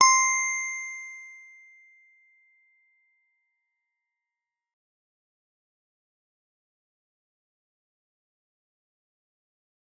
G_Musicbox-C6-f.wav